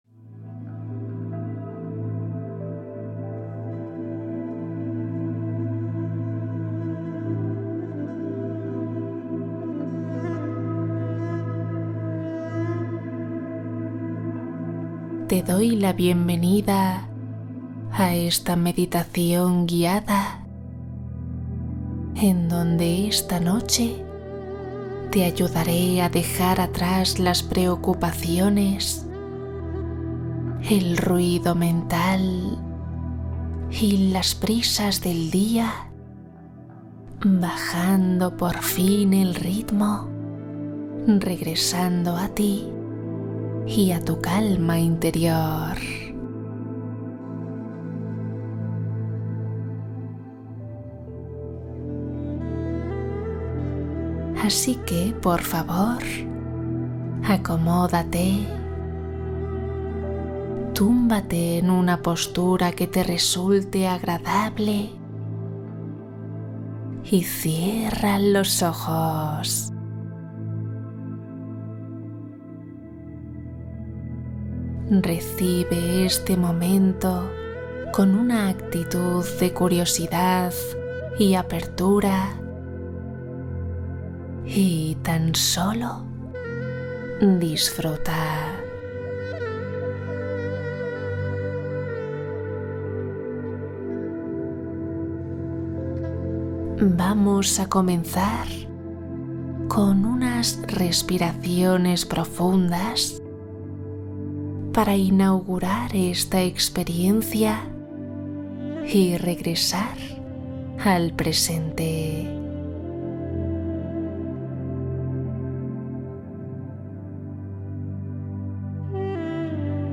Sueño rápido y profundo Meditación para liberar el insomnio